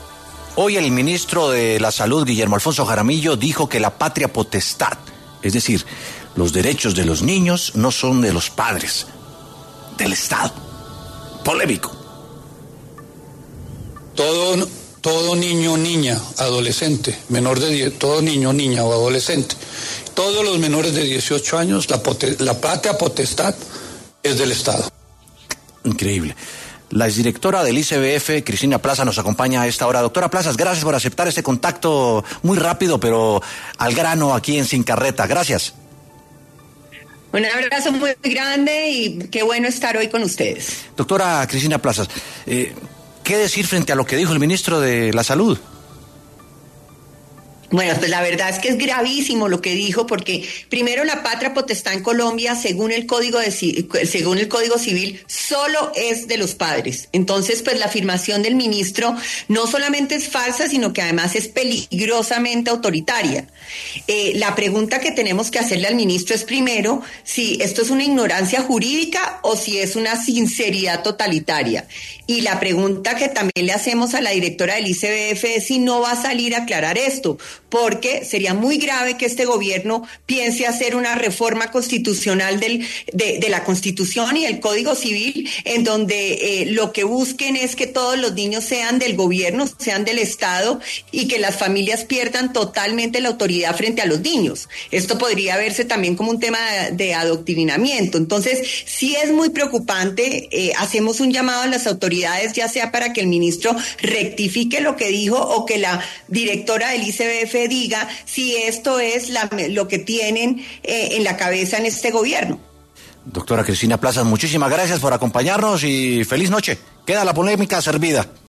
La exdirectora del ICBF, Cristina Plazas, rechazó en W Sin Carreta las declaraciones del ministro de Salud sobre que la patria potestad de los niños sea del Estado.
La exdirectora del Instituto Colombiano de Bienestar Familiar (ICBF), Cristina Plazas, pasó por los micrófonos de W Sin Carreta para hablar sobre el tema.